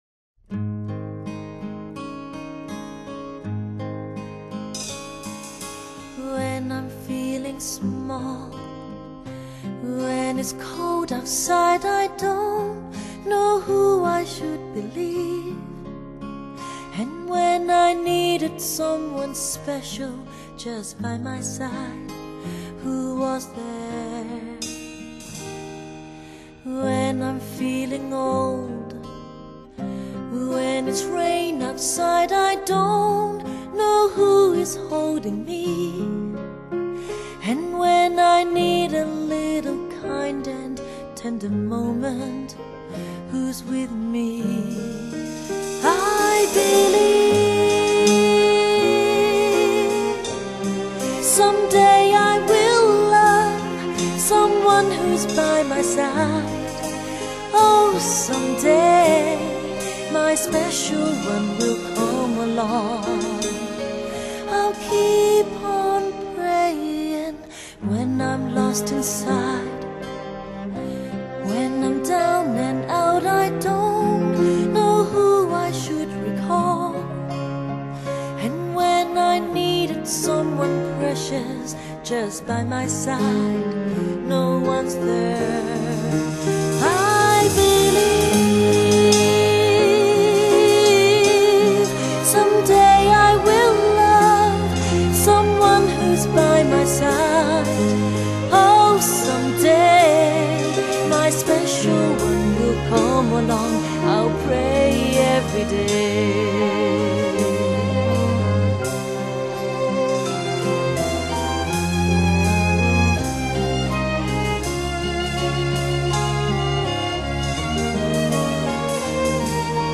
新世代 Hi Fi天后 极品录音中的极品 骇人听闻的高超真实感 将女性的性感与感性 充满魅力的呈现出来